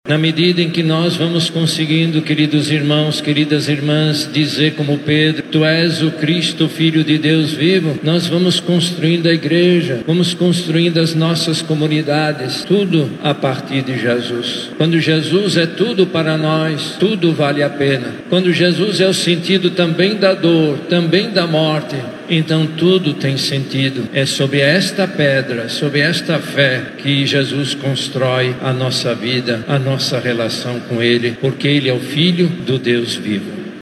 Durante a homilia, o arcebispo de Manaus, Cardeal Leonardo Steiner, destacou a trajetória do apóstolo: suas quedas, reconciliações e o martírio que selou seu testemunho de fé.